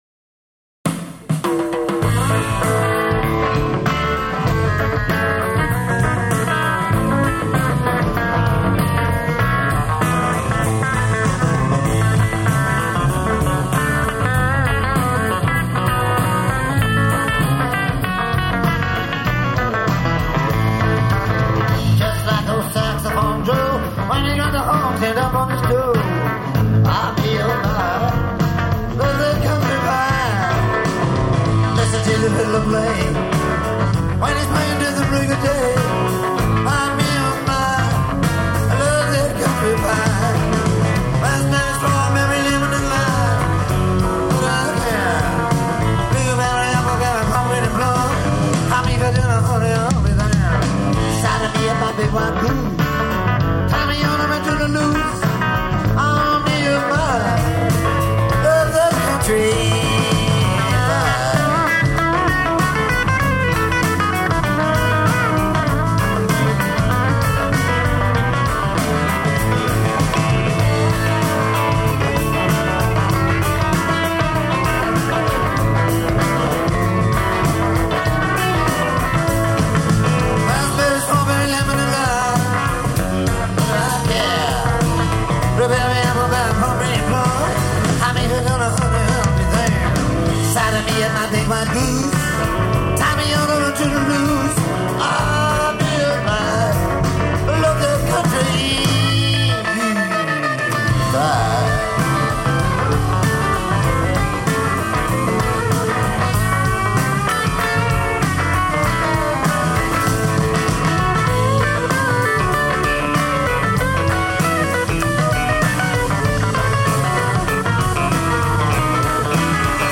live 2000 in England